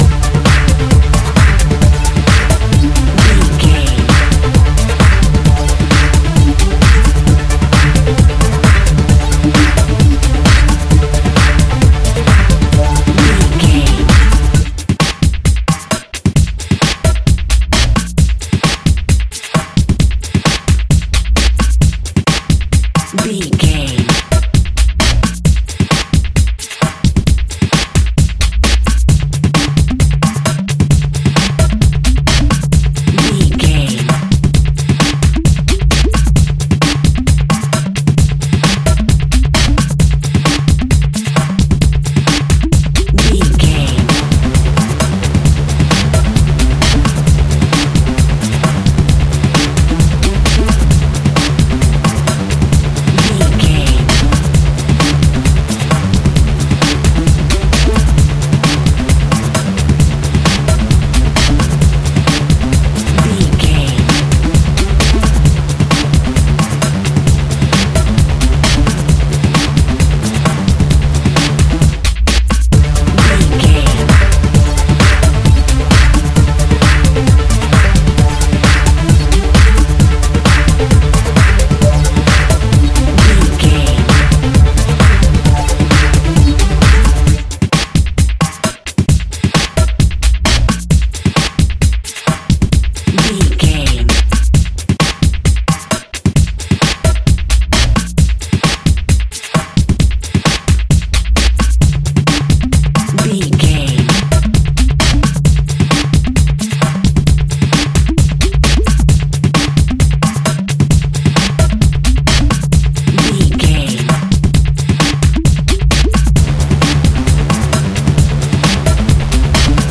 Ionian/Major
breakbeat
energetic
pumped up rock
power rock
electro pop
synth pop
uplifting
powerful
electro house
drums
synth bass
synth lead
percussion